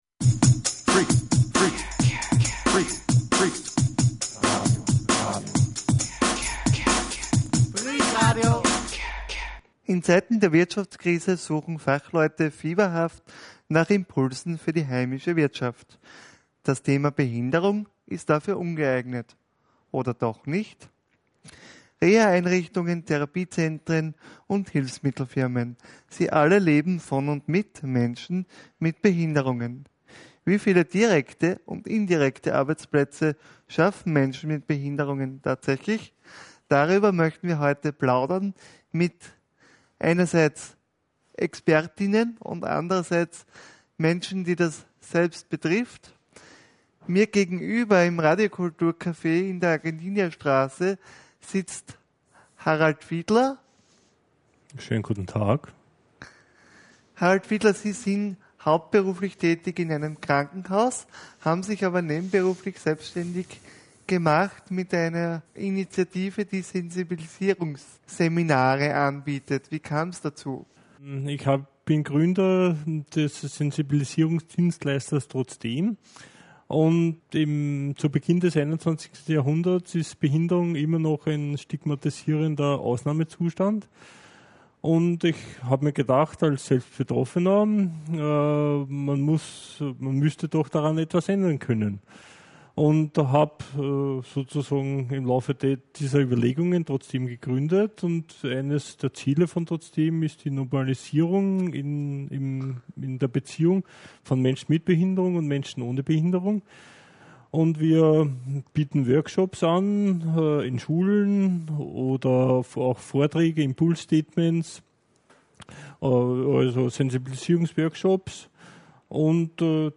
Wie viele direkte und indirekte Arbeitsplätze schaffen behinderte Menschen tatsächlich? Darüber sprechen wir mit unseren Gästen: